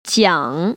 [jiǎng]
지앙